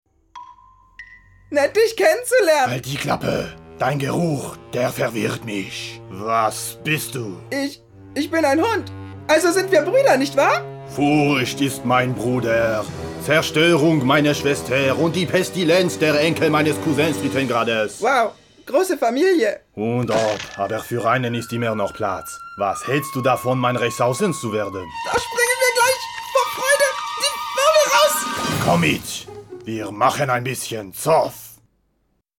Sprechprobe: Industrie (Muttersprache):
german voice over artist